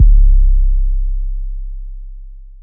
Low Life 808.wav